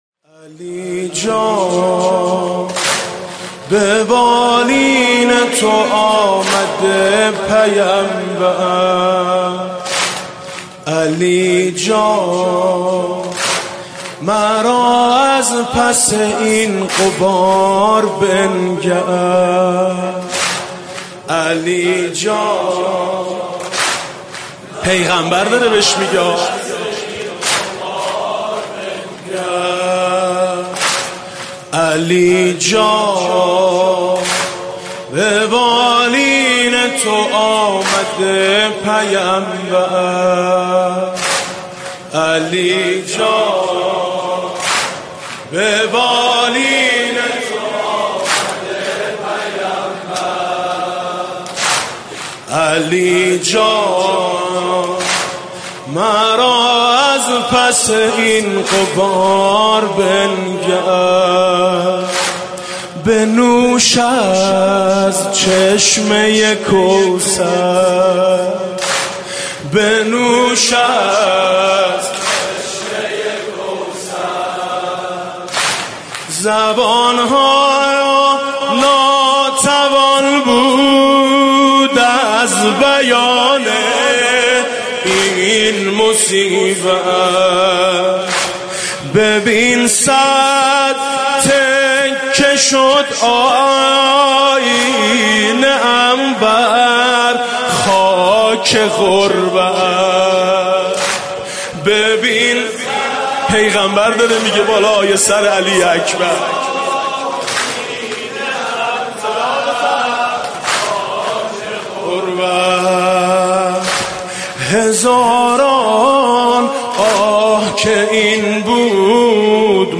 شب هشتم محرم97